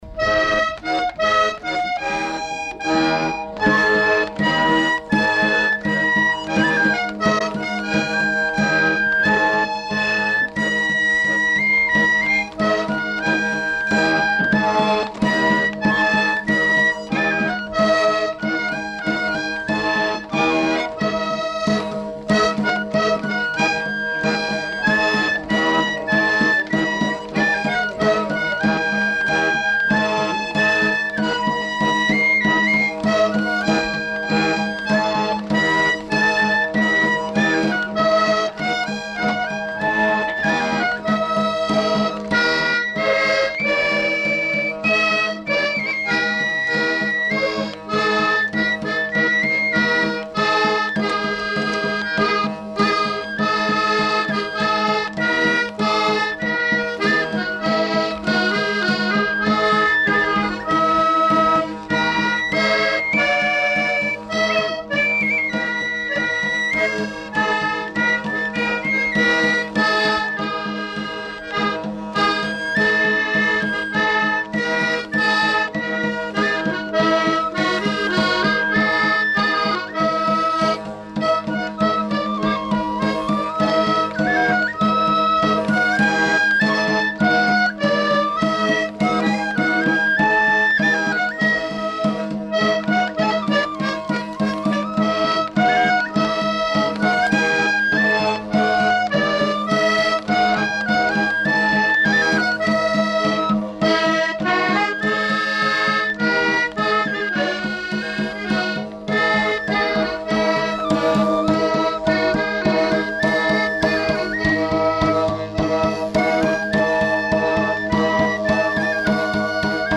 Aire culturelle : Béarn
Lieu : Castet
Genre : morceau instrumental
Instrument de musique : flûte à trois trous ; tambourin à cordes ; accordéon diatonique
Danse : branlo bash
Notes consultables : Cette interprétation n'est qu'une bribe du branlo bash.